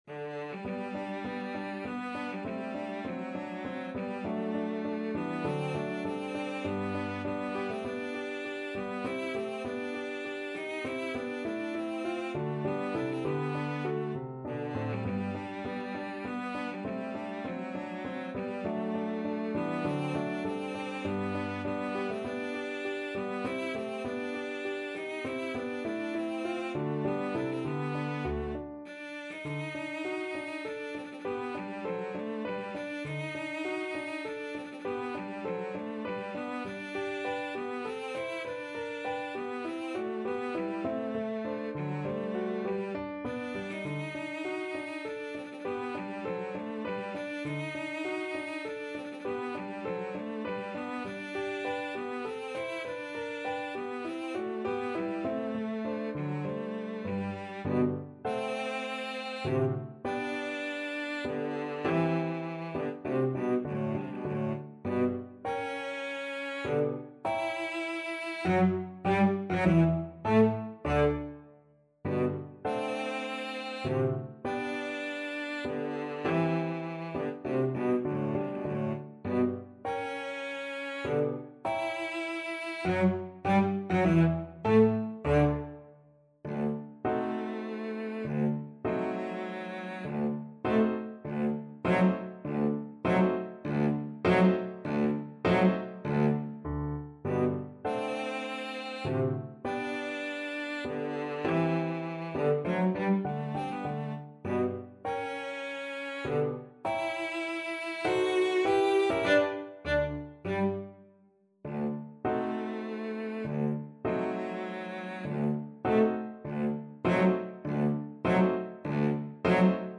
3/4 (View more 3/4 Music)
Menuetto Moderato e grazioso
Classical (View more Classical Cello Music)